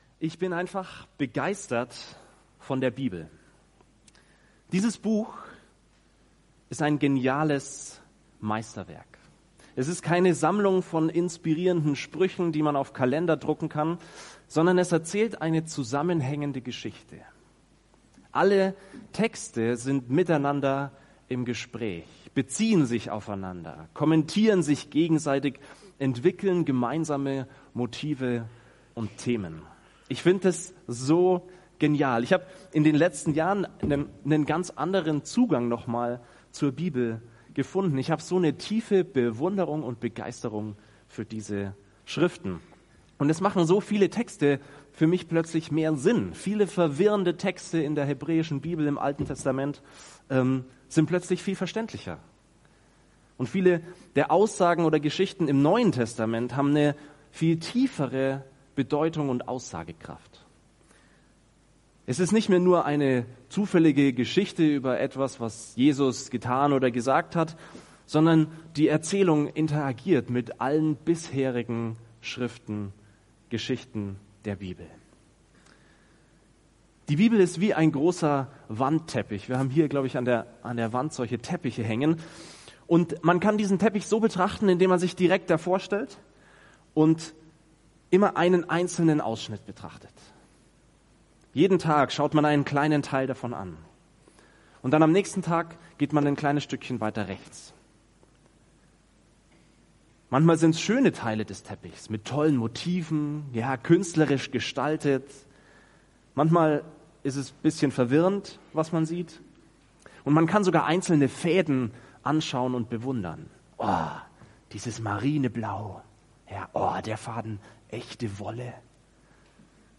Wo der Himmel die Erde berührt Prediger